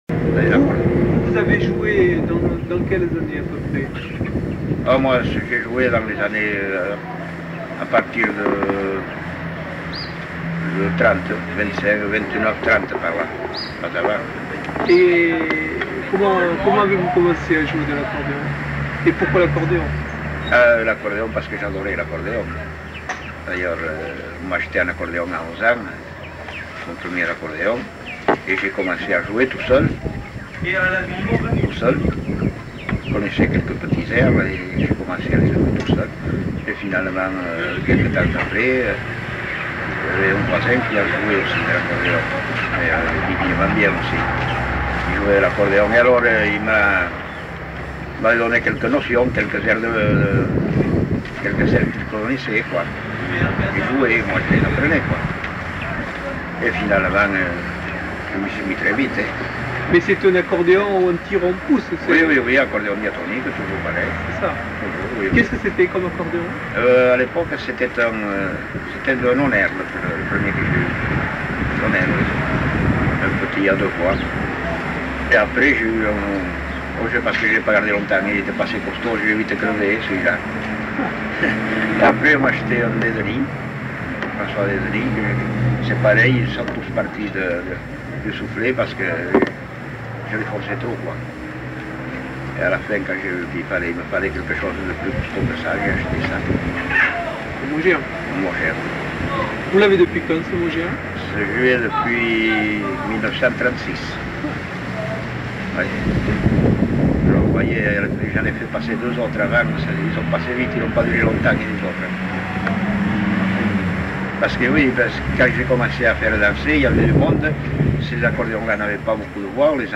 Lieu : Mimizan
Genre : récit de vie